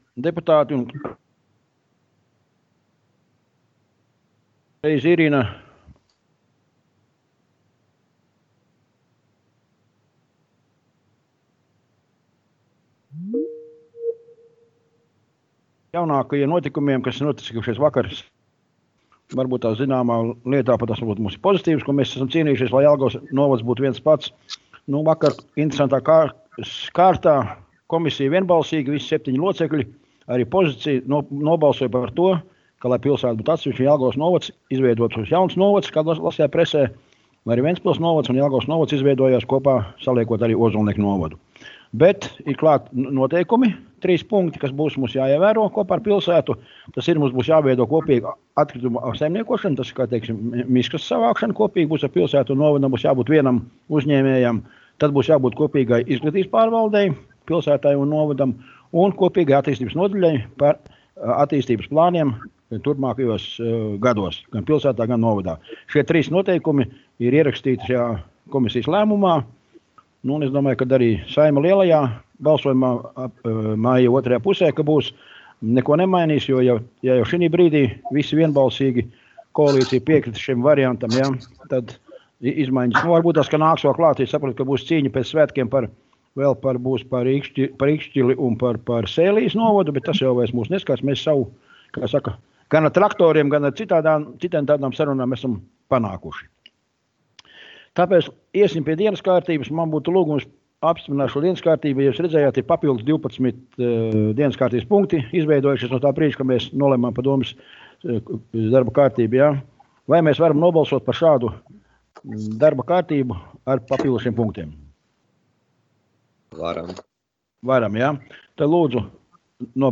Domes sēde Nr. 9